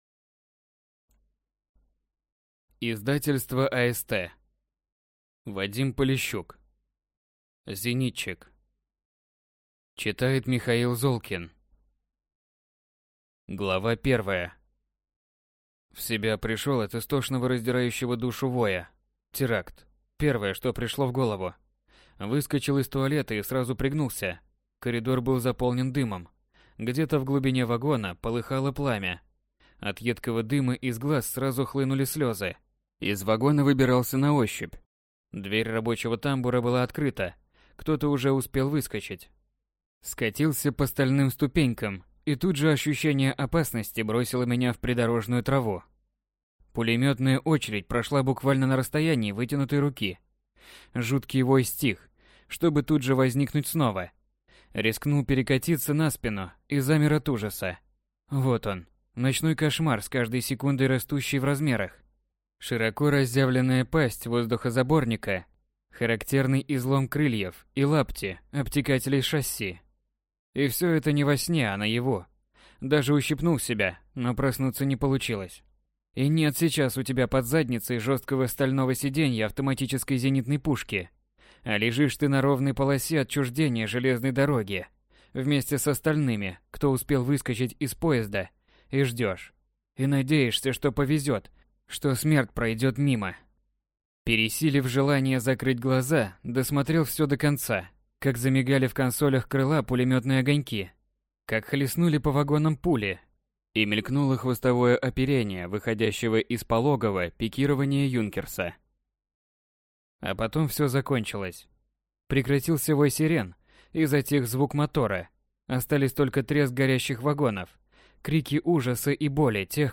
Аудиокнига Зенитчик: Зенитчик. Гвардии зенитчик. Возвращенец | Библиотека аудиокниг